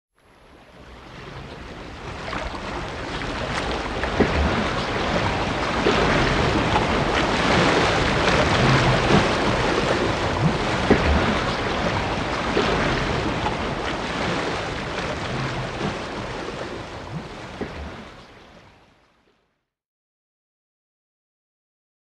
جلوه های صوتی
دانلود صدای وال 6 از ساعد نیوز با لینک مستقیم و کیفیت بالا
برچسب: دانلود آهنگ های افکت صوتی انسان و موجودات زنده دانلود آلبوم صدای حیوانات آبی از افکت صوتی انسان و موجودات زنده